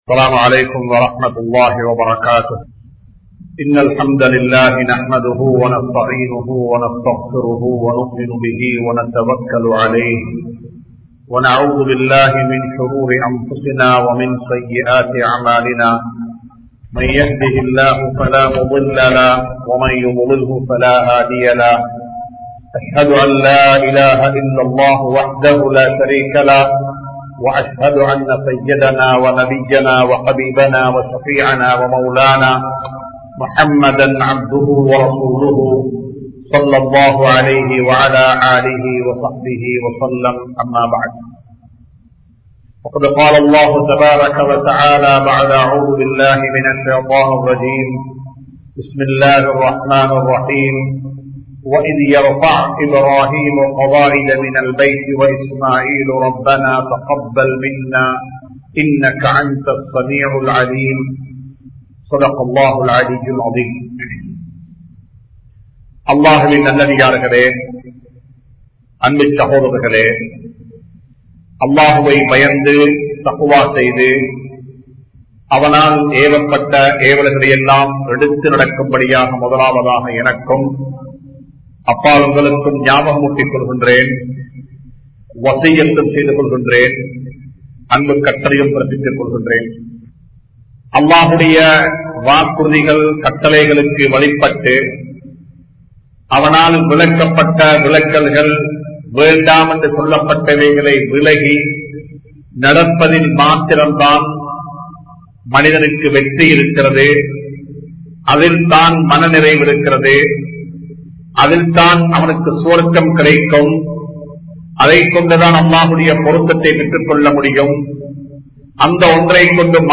Allahvin Poruththam Veanduma? (அல்லாஹ்வின் பொருத்தம் வேண்டுமா?) | Audio Bayans | All Ceylon Muslim Youth Community | Addalaichenai
Dehiwela, Muhideen (Markaz) Jumua Masjith